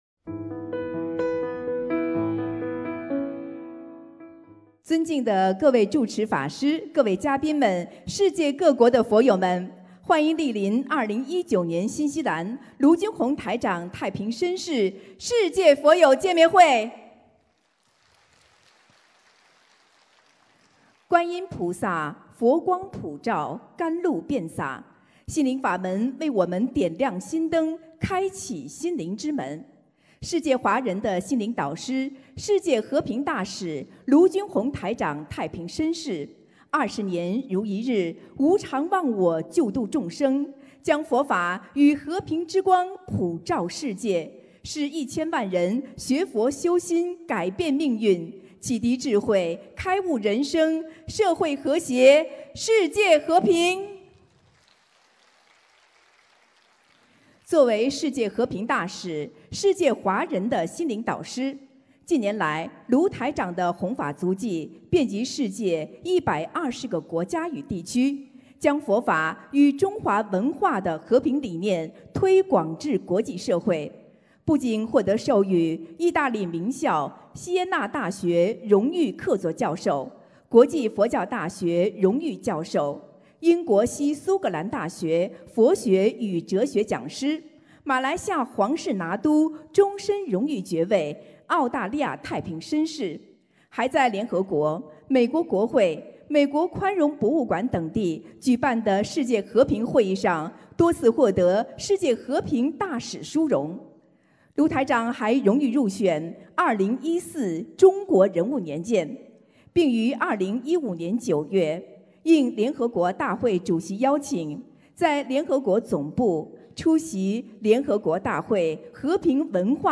2019年11月8日新西兰见面会开示（视音文图） - 2019-2020年 - 心如菩提 - Powered by Discuz!